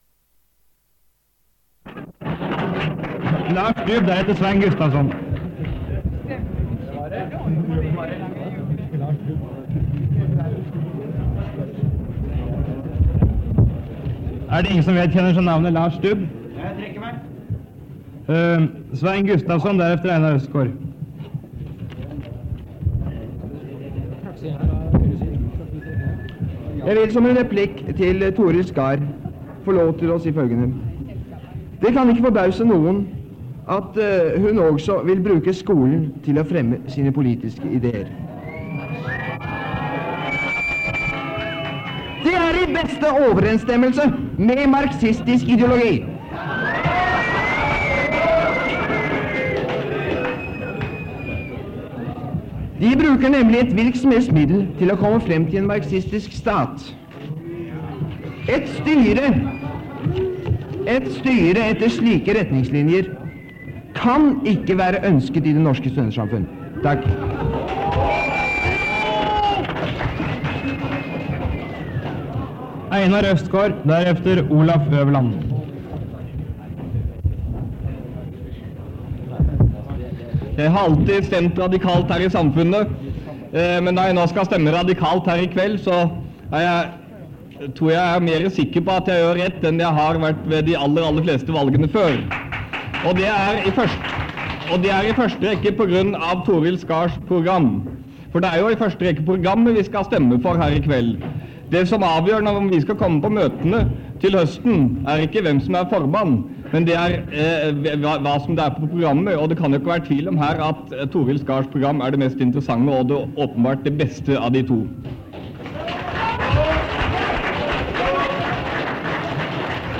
Det Norske Studentersamfund, Generalforsamling, 23.04.1960